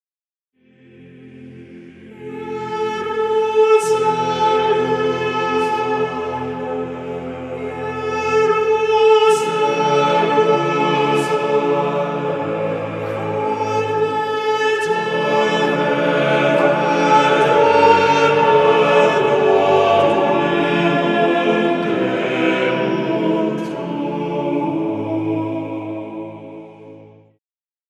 motets